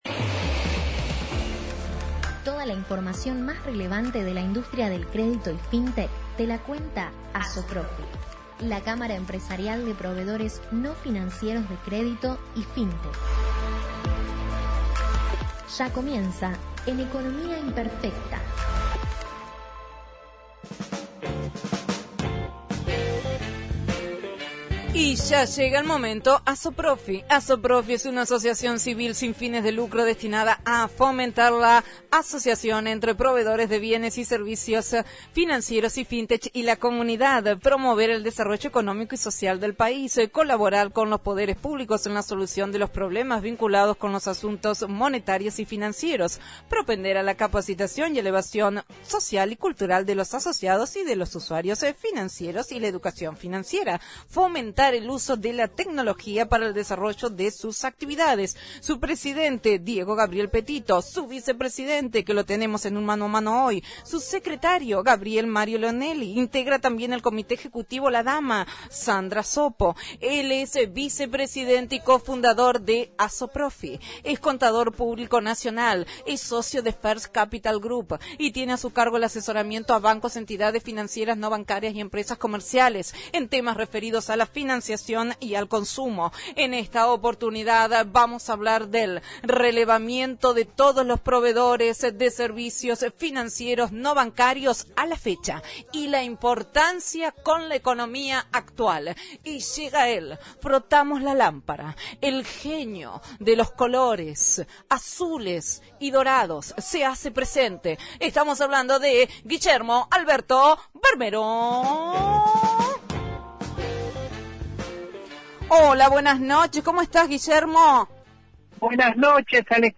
ASOPROFI – COLUMNA RADIAL – RADIO AM 1420 Viernes 06/08/2021 – ” Relevamiento de todos los proveedores de servicios financieros no bancarios a la fecha”